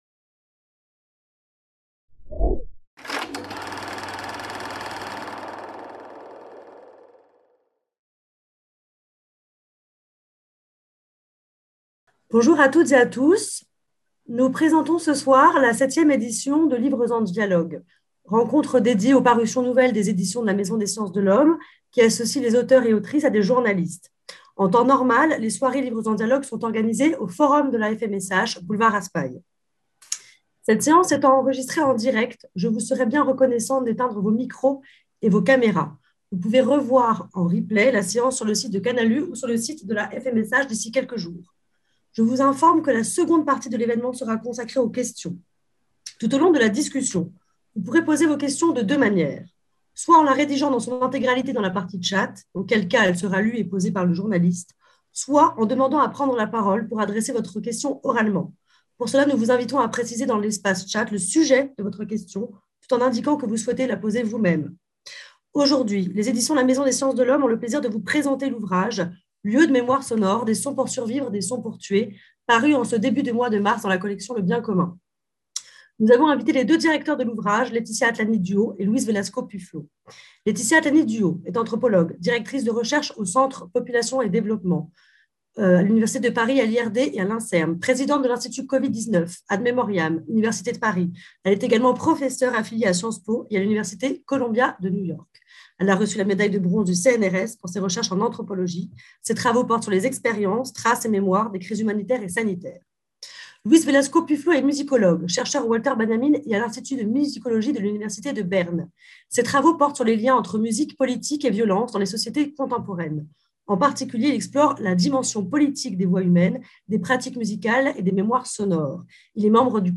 Soirée de présentation de l'ouvrage "Lieux de mémoire sonore" | Canal U